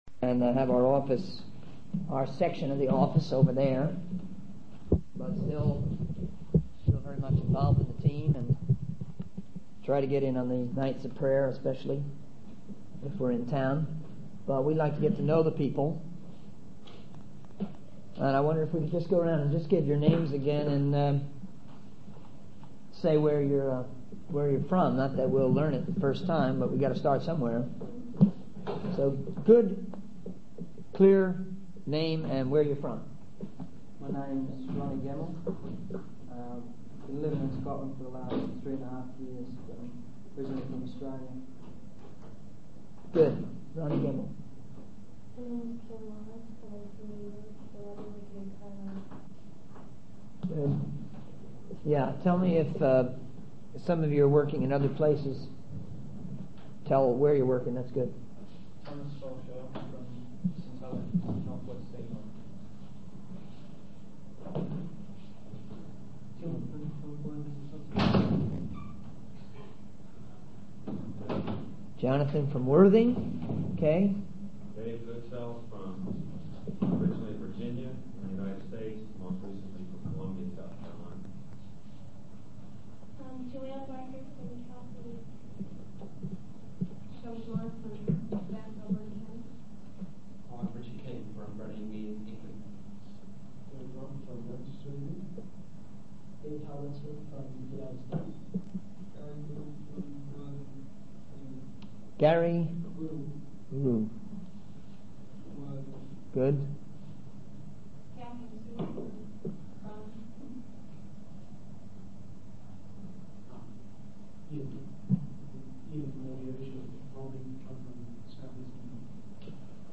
In this sermon, the speaker discusses the challenges and unpredictability of doing God's work in Brahman. They mention the lack of funds and the need to be flexible in their transportation arrangements. The speaker emphasizes the importance of training and preparation for the individuals involved in the work, as they will be the ones representing God to others.